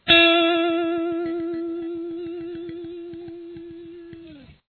Vibrato
A basic Vibrato is performed by rapidly vibrating a note by bending and releasing it with your fret hand. We’re not bending it up to a higher pitch per se, just “shaking” the string.
vibrato.mp3